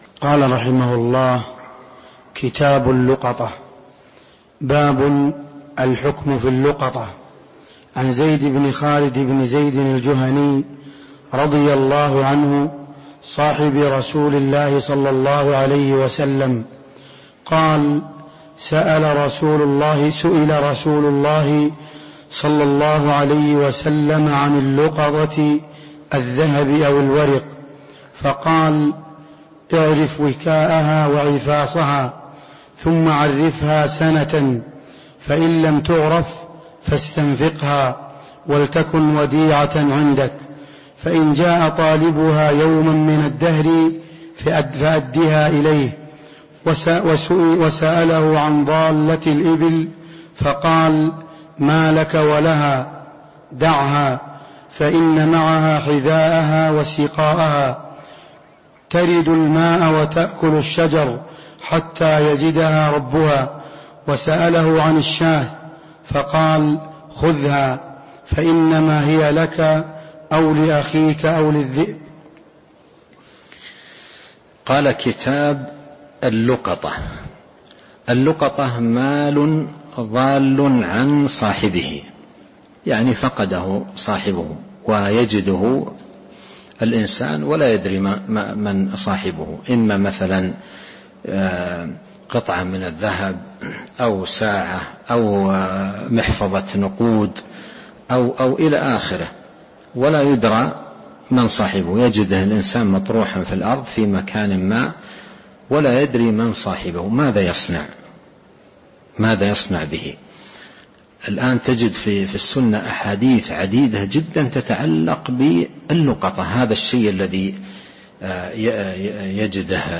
تاريخ النشر ١٨ ربيع الأول ١٤٤٣ هـ المكان: المسجد النبوي الشيخ